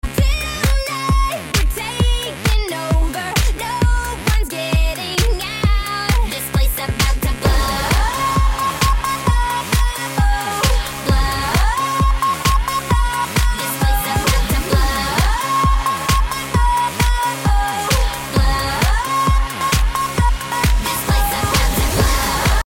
Танцевальные рингтоны
Клубные рингтоны
женский голос клубняк